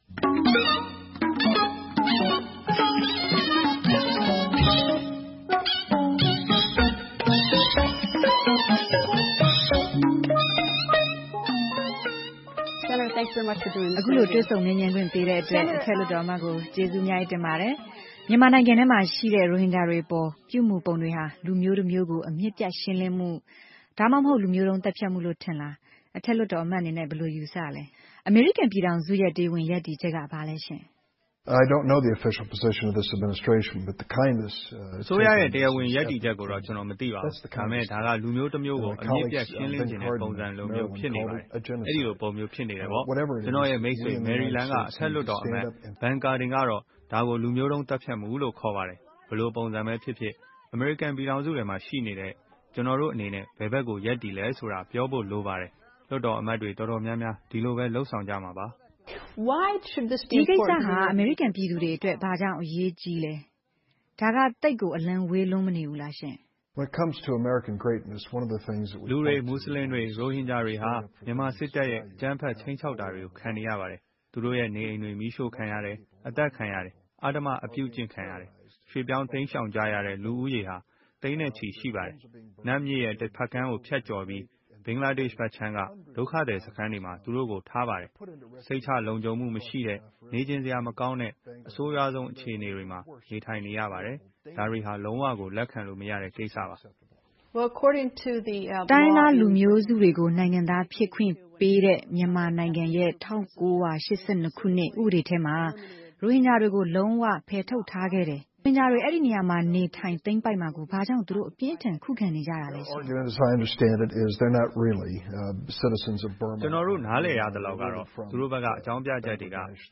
မြန်မာ့ဒီမိုကရေစီရေး တွေ့ဆုံမေးမြန်းခန်းမှာ မြန်မာနိုင်ငံ ရခိုင်ပြည်နယ်မြောက်ပိုင်းမှာ ရိုဟင်ဂျာမူစလင် သိန်းနဲ့ချီ အိုးအိမ်စွန့်ခွါ ထွက်ပြေးနေရတဲ့ အခြေအနေဟာ လူမျိုးတုံးသုတ်သင်ရှင်းလင်းတဲ့ပုံစံမျိုး ဖြစ်နေတယ်ဆိုပြီး ဒီအခြေအနေကို လုံးဝလက်မခံနိုင်တဲ့အကြောင်း ဒီမိုကရက်အထက်လွှတ်တော်အမတ် Dick Durbin က ပြောပါတယ်။ အထက်လွှတ်တော်အမတ်ကို VOA သတင်းထောက် Greta Van Susteren တွေ့ဆုံမေးမြန်းထားပါတယ်။